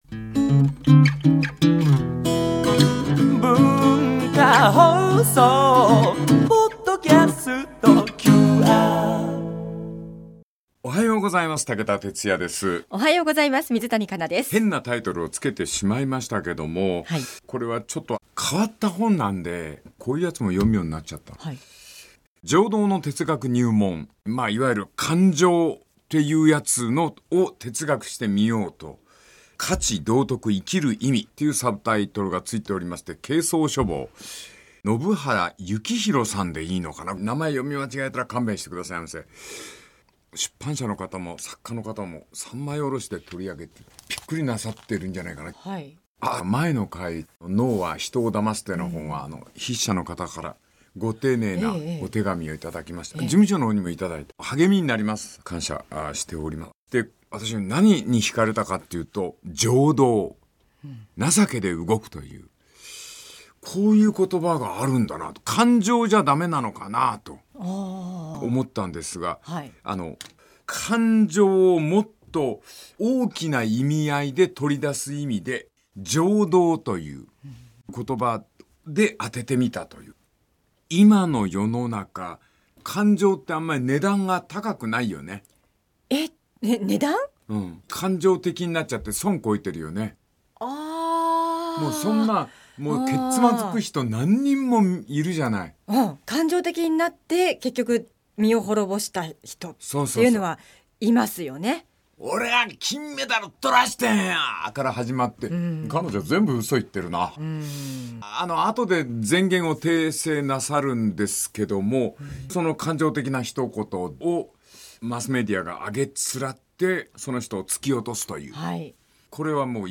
温かさと厳しさを併せ持つ武田鉄矢が毎週テーマに添ってさまざまな語りを展開。